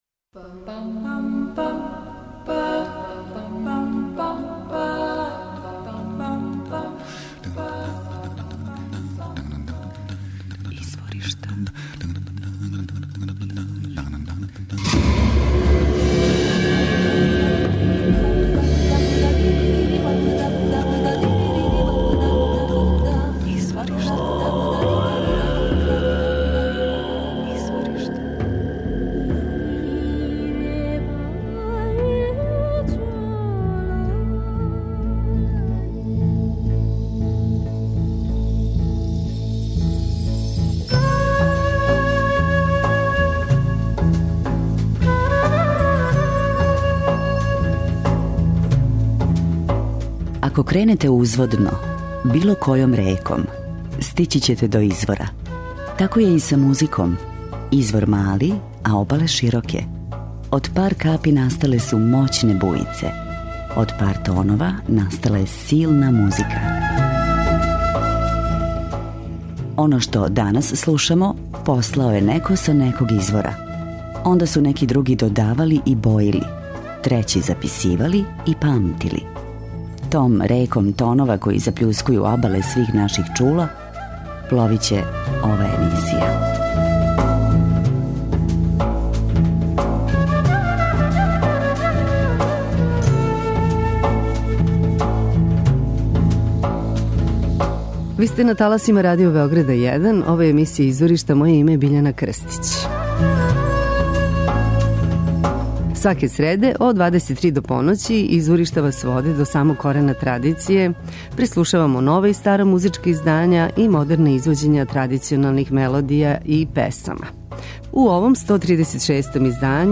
нгони (афричка врста лауте)
комбинујује world fusion са традиционалним афричким попом.